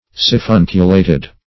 Siphunculated \Si*phun"cu*la`ted\
siphunculated.mp3